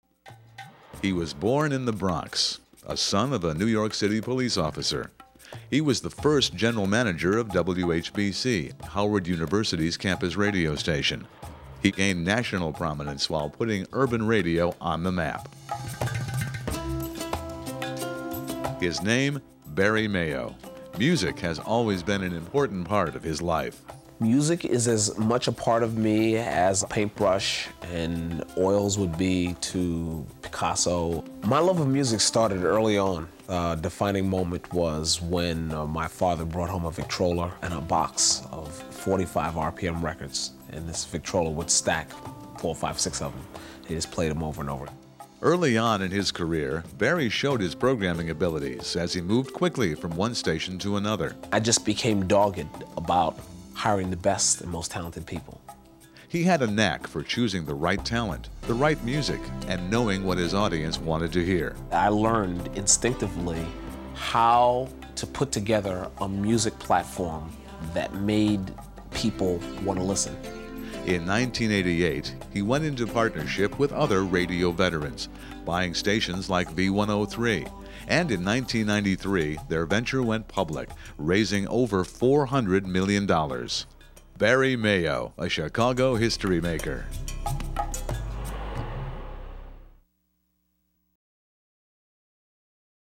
• Program: Interviews